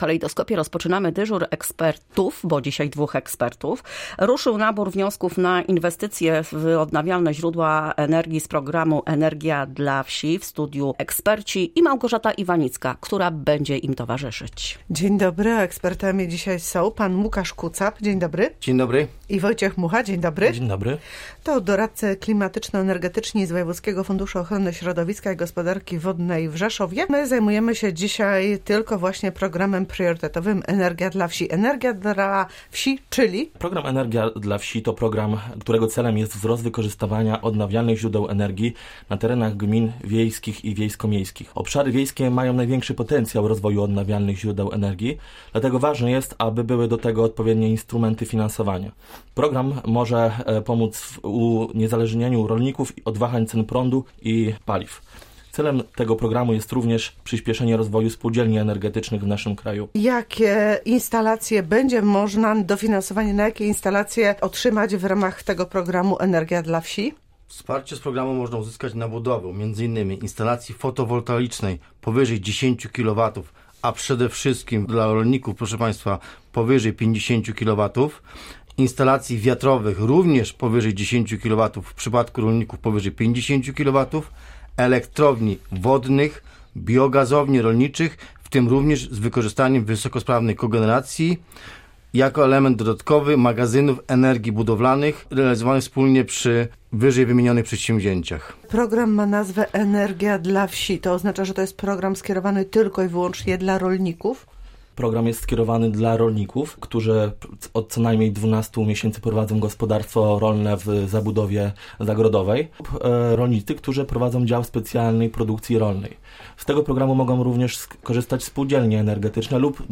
Doradcy Klimatyczno-Energetyczni Wojewódzkiego Funduszu Ochrony Środowiska i Gospodarki Wodnej w Rzeszowie podczas dyżuru eksperta w Kalejdoskopie przekazywali wiedzę o Programie priorytetowym Energia dla wsi.
Słuchacze pytali podczas dyżuru o programy Moja elektrownia wiatrowa i Mój prąd.